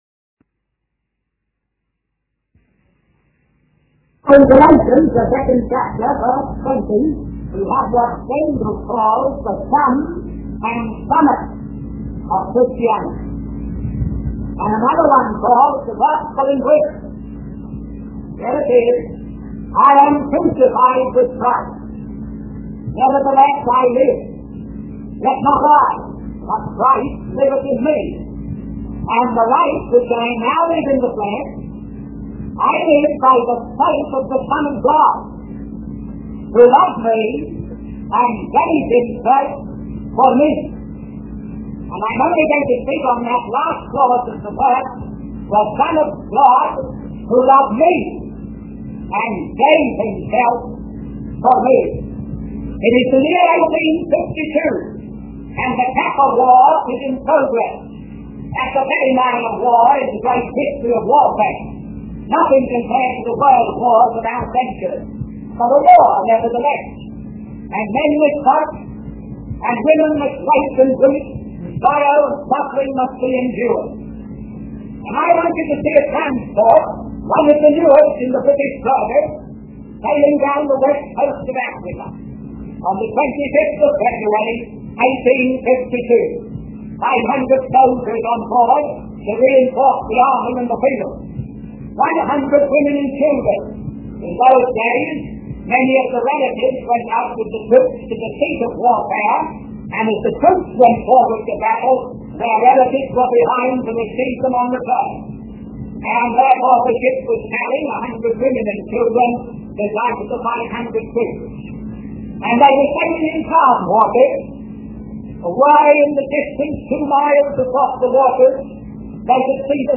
In this sermon, the preacher discusses the story of Joseph from the Bible.